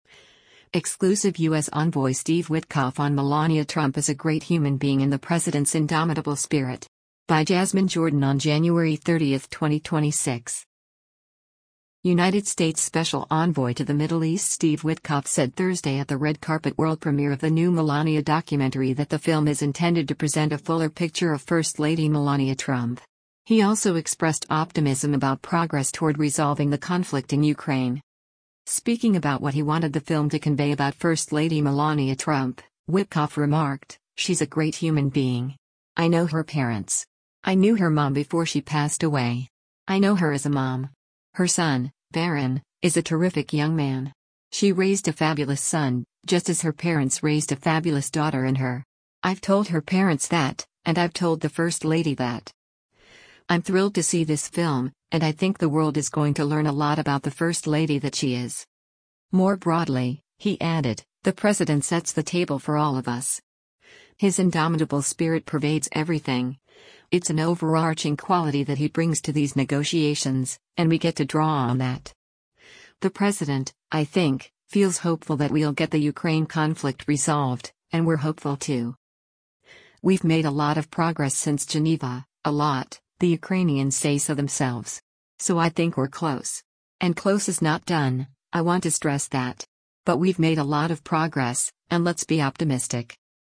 United States Special Envoy to the Middle East Steve Witkoff said Thursday at the red carpet world premiere of the new Melania documentary that the film is intended to present a fuller picture of first lady Melania Trump. He also expressed optimism about progress toward resolving the conflict in Ukraine.